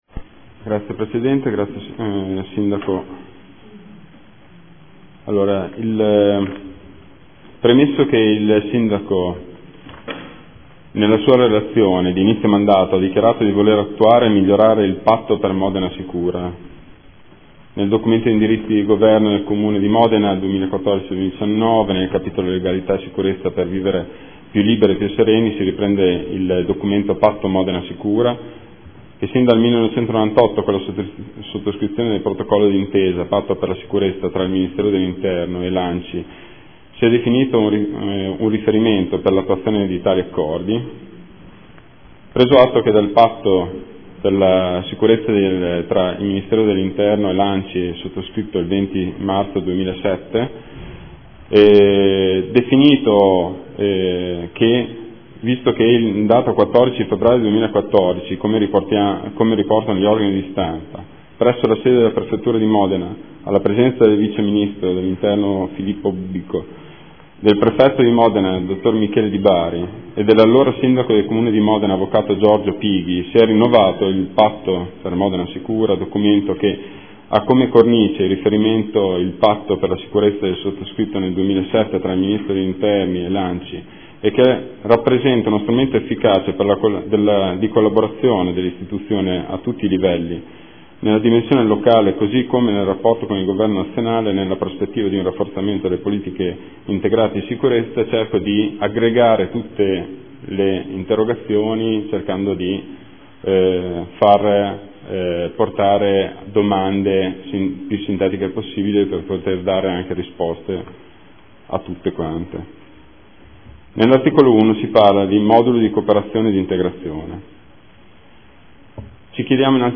Bortolotti Marco — Sito Audio Consiglio Comunale
Seduta del 9/10/2014. Presenta congiuntamente le seguenti interrogazioni: Prot. Gen. 112722 - Prot. Gen. 112723 - Prot. Gen. 112724 - Prot. Gen. 112725 - Prot. Gen. 112728 - Prot. Gen. 112731 - Prot. Gen. 112737 - Prot. Gen. 112739